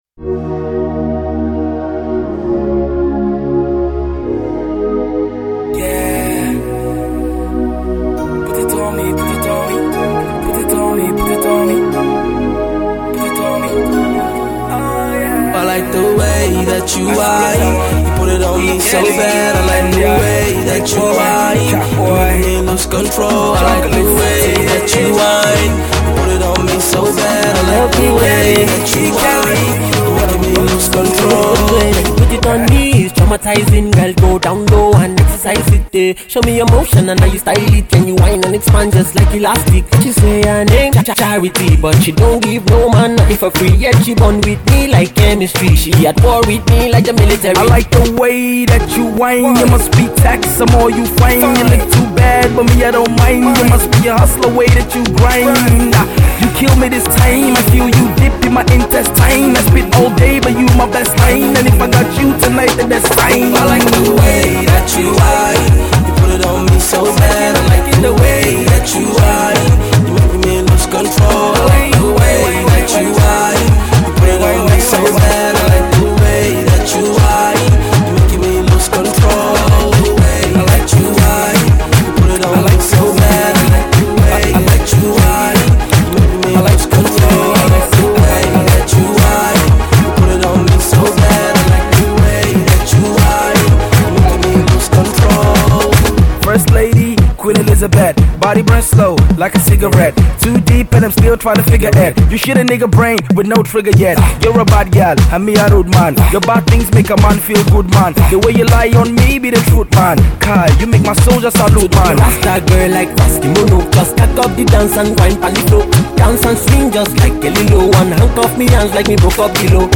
another banging tune for the clubs